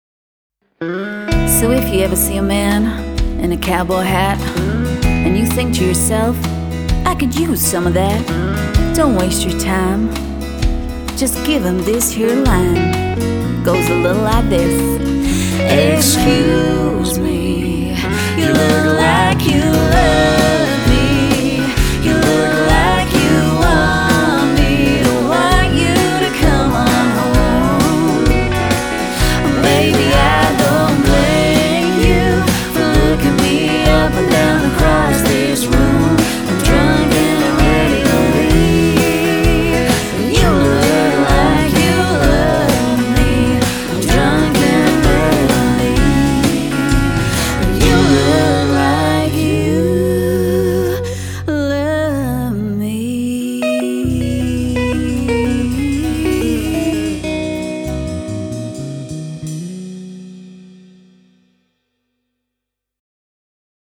a high-energy 6-piece country pop party band